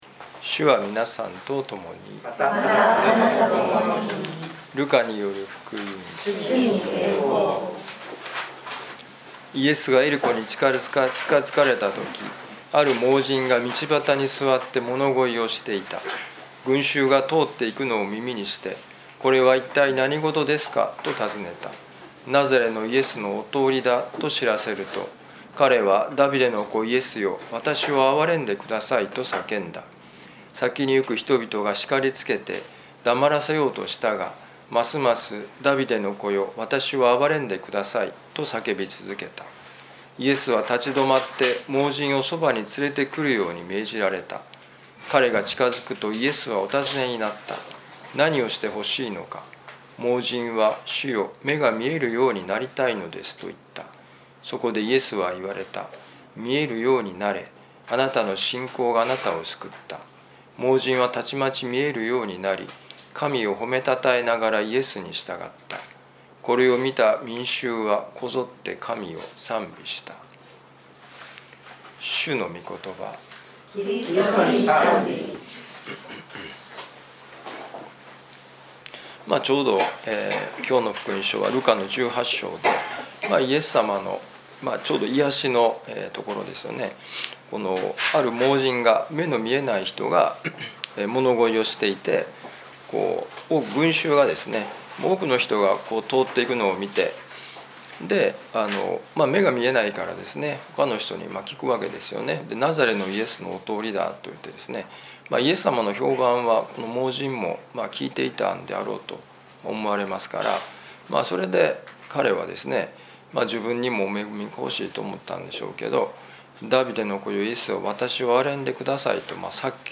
ルカ福音書 18章35-43節「本当のことが見えたら」2025年11月17日年いやしのミサ旅路の里